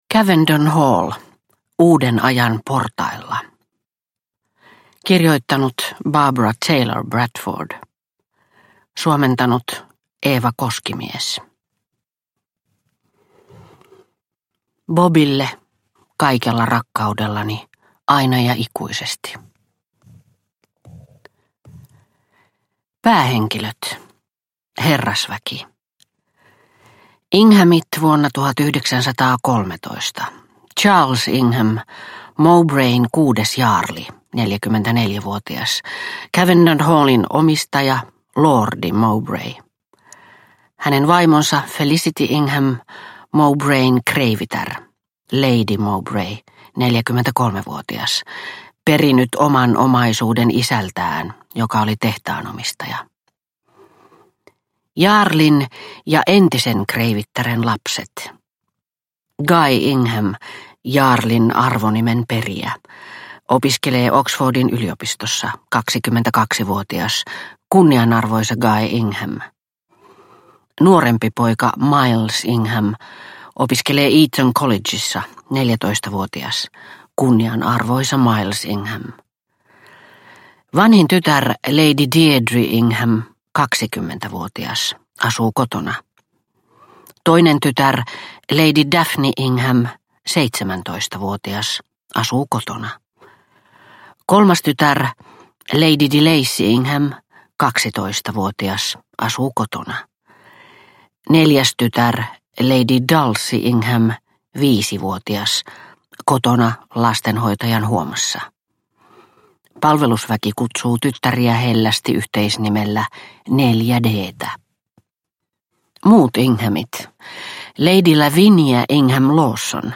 Cavendon Hall – Uuden ajan portailla – Ljudbok – Laddas ner